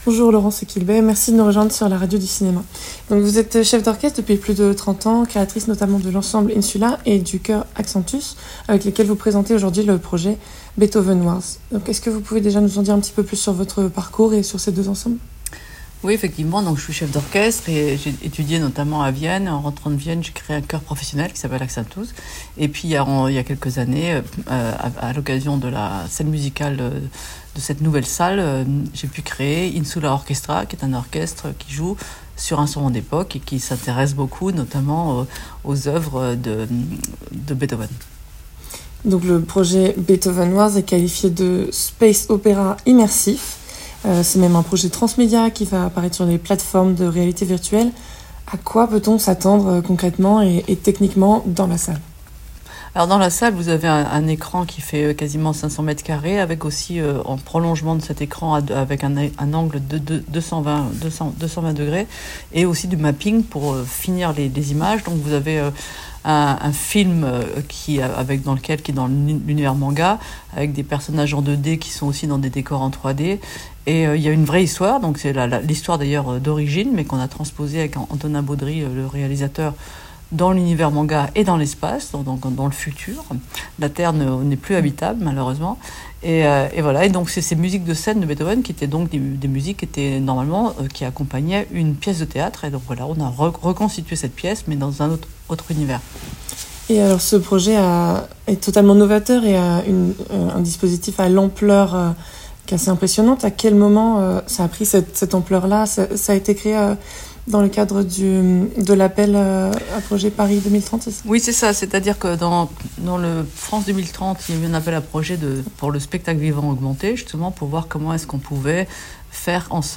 Dans cette interview, elle nous dévoile les coulisses de cette création audacieuse.